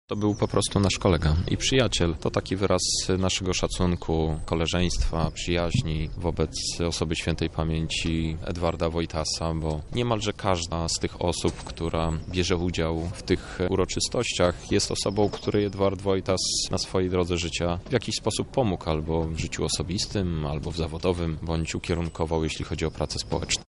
-To był dobry człowiek – mówi europoseł Krzysztof Hetman, były marszałek województwa lubelskiego z ramienia PSL